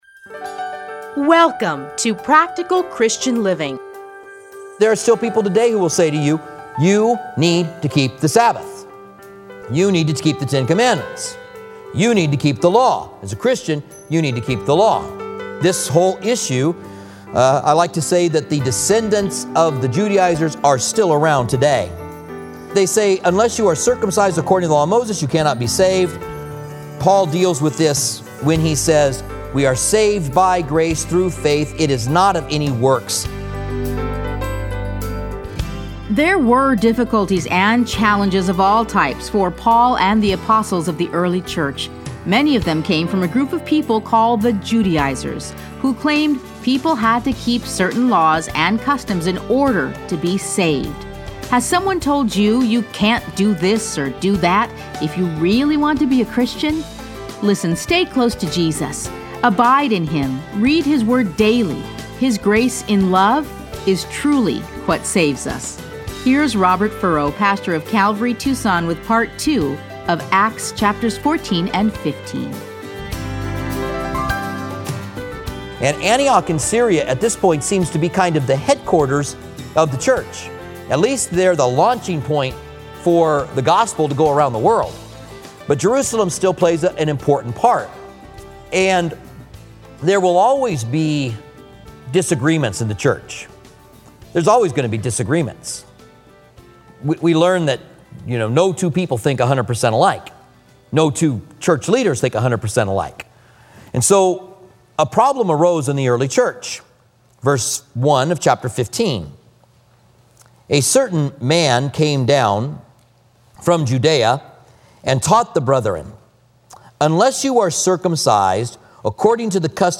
Listen to a teaching from Acts 14.